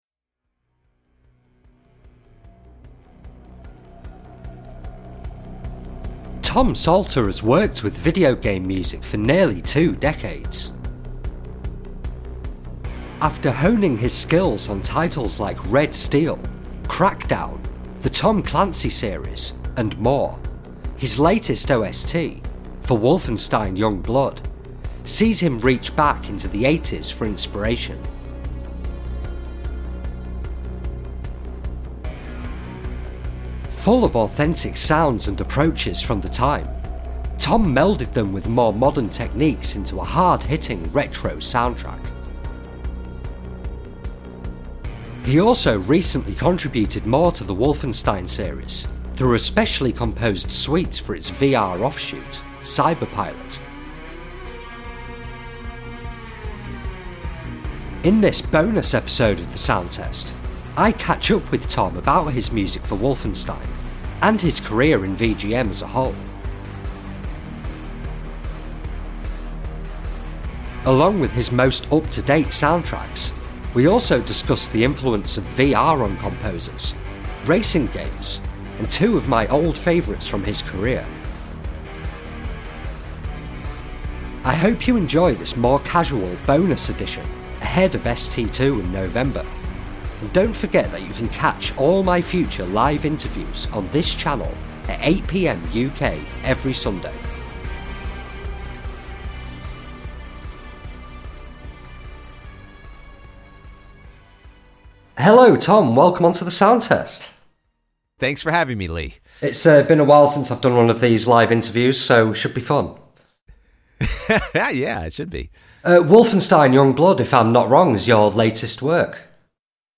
In this bonus episode of The Sound Test, I catch up with Tom about his music for Wolfenstein, and his career in VGM as a whole. Along with his most up to date soundtracks, we also discuss the influence of VR on composers, racing games and two of my old favourites from his career.